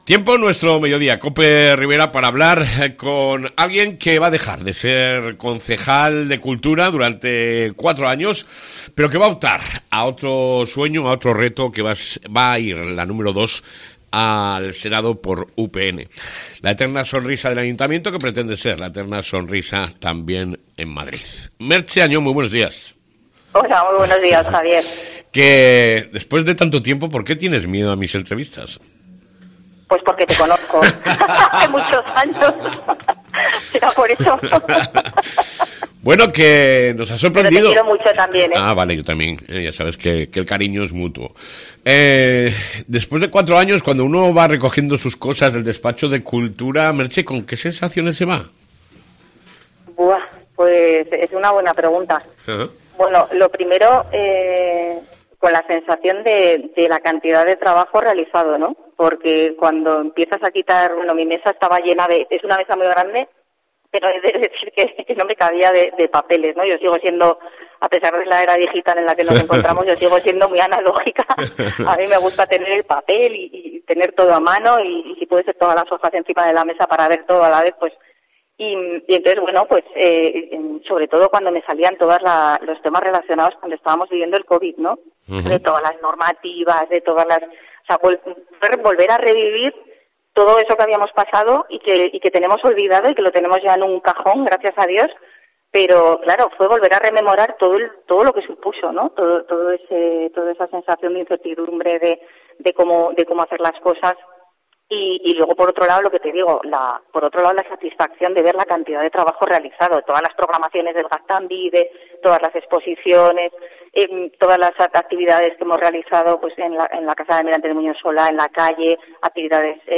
ENTREVISTA CON MERCHE AÑON, CONCEJAL DE CULTURA